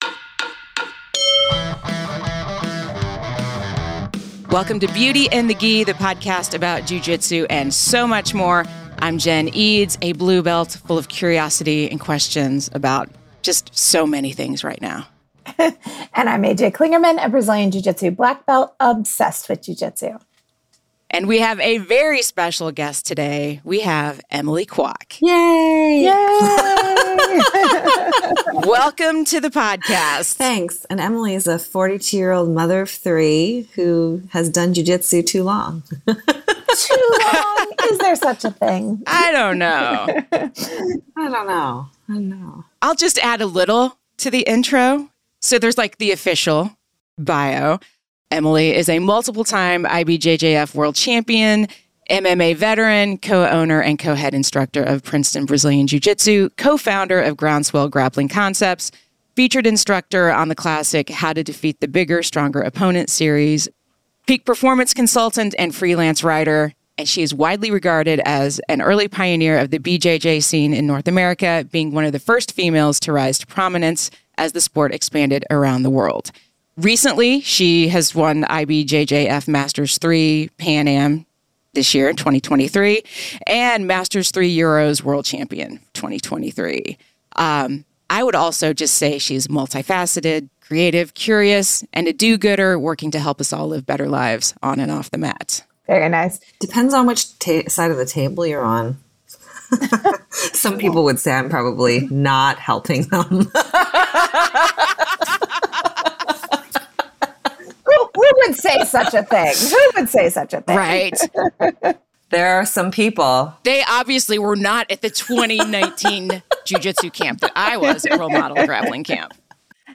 Listen up for an insightful conversation on the world of Jiu Jitsu and its impact on personal and professional growth.